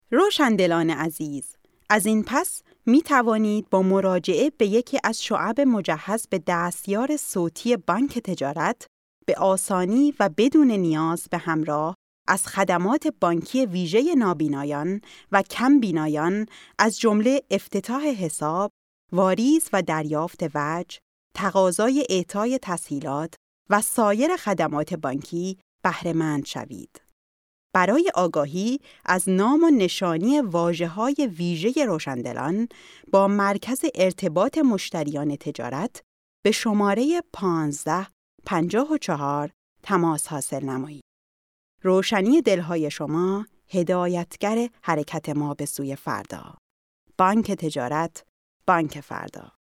Sprechprobe: Werbung (Muttersprache):
01 Bankwerbung.mp3